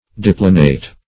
Search Result for " deplanate" : The Collaborative International Dictionary of English v.0.48: Deplanate \De*pla"nate\, a. [L. deplanetus, p. p. of deplanare to make level.